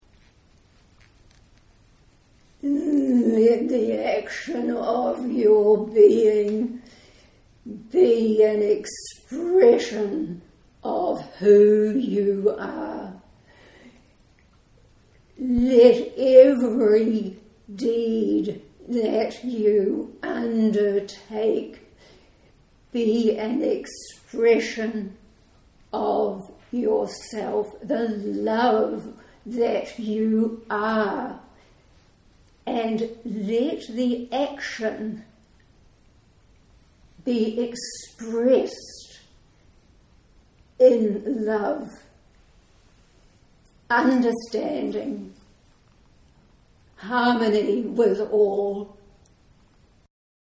Bear in mind that it is a live recording and please excuse the deficiencies in sound quality.
Posted in Audio recording, Channelled messages, Metaphysical, Spirituality, Trance medium